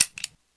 p228_clipout.wav